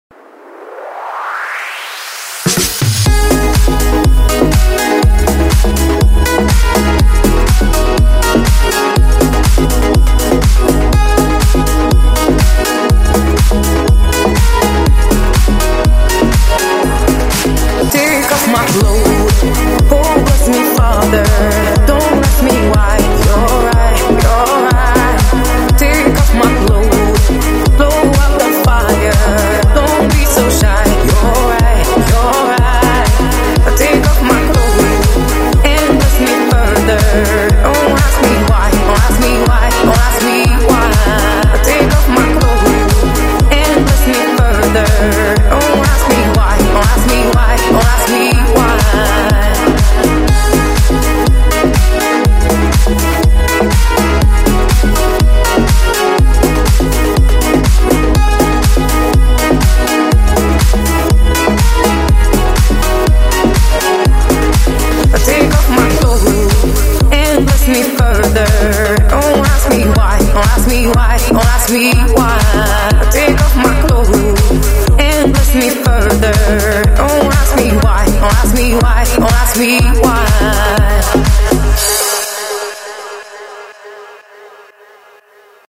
• Качество: 128, Stereo
красивые
спокойные